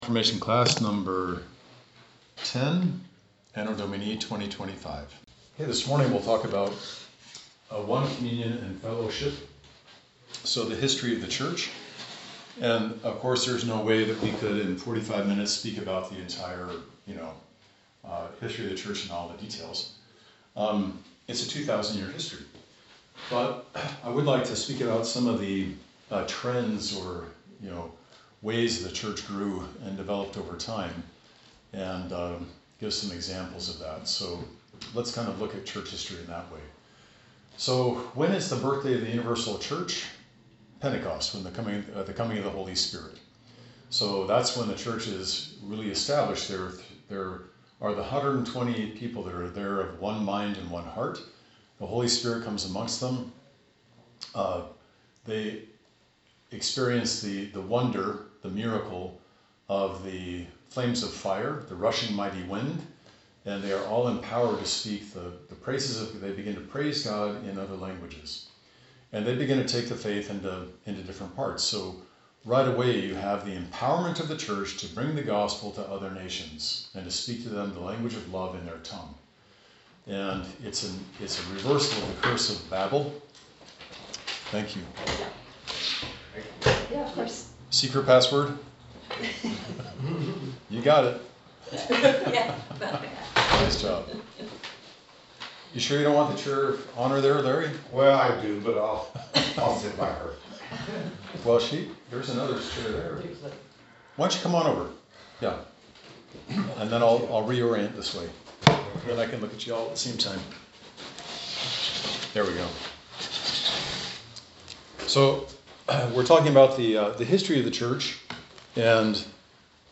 Adult Confirmation Class #10